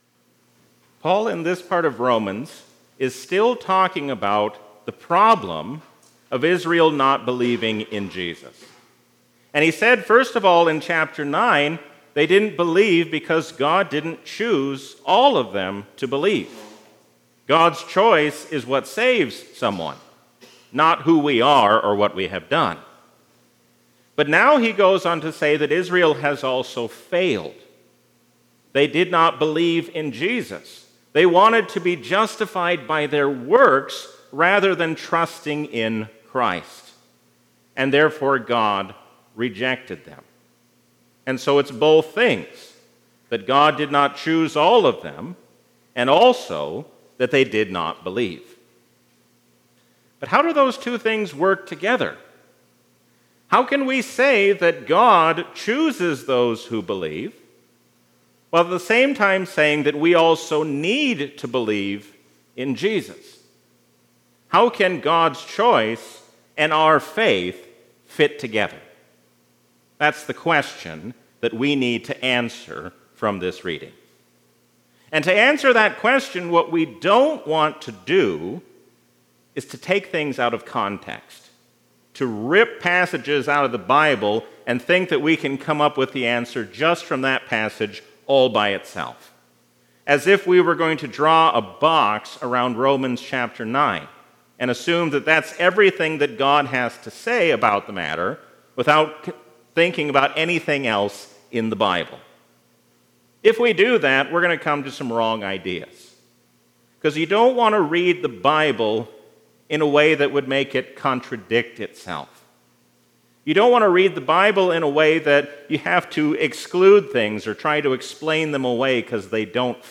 A sermon from the season "Trinity 2022."